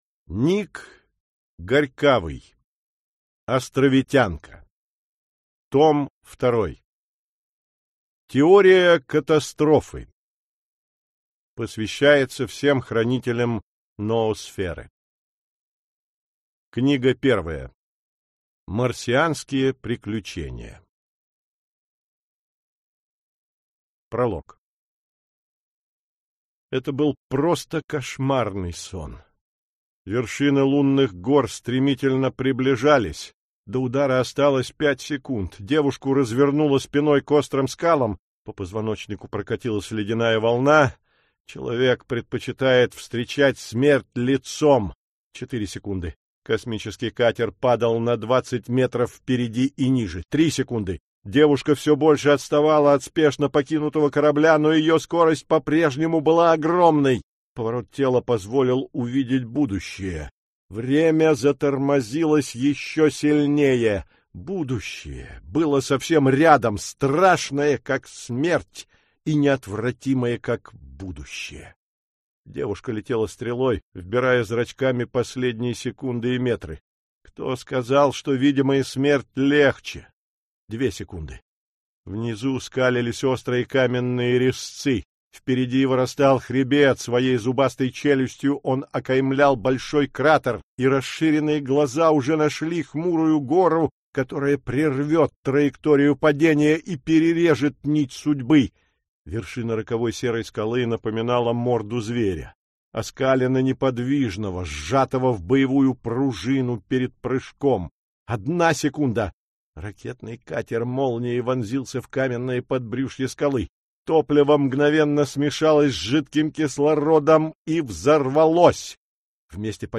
Аудиокнига Теория катастрофы. Книга 1. Марсианские приключения | Библиотека аудиокниг